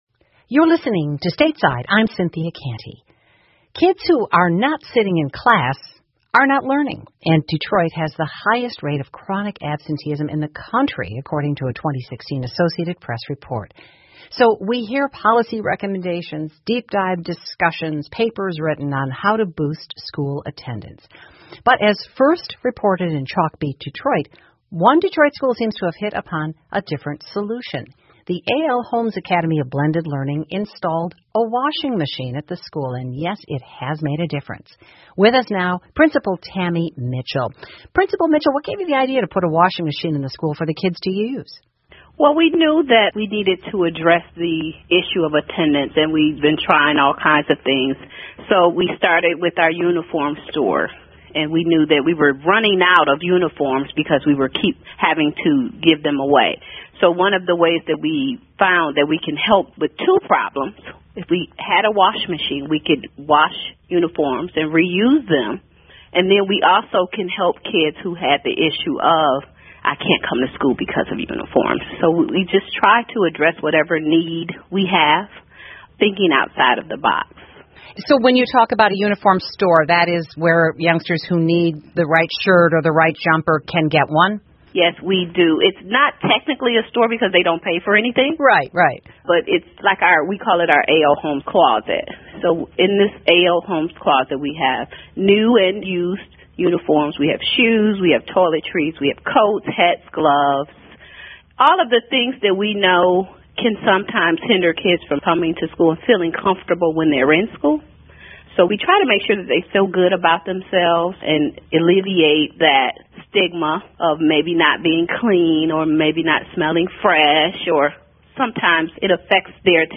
密歇根新闻广播 洗衣机如何减少底特律学校旷工的 听力文件下载—在线英语听力室